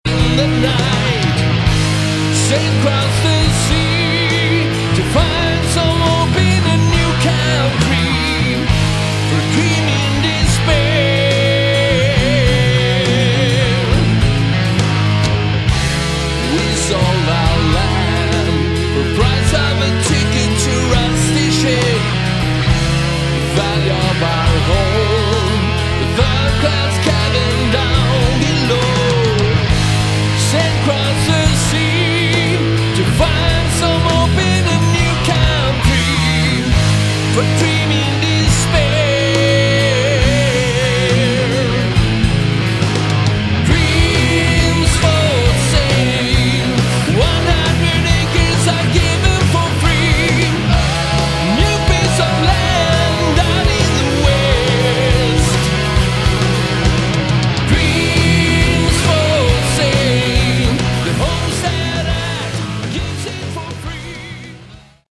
Category: Hard Rock
vocals, guitars
bass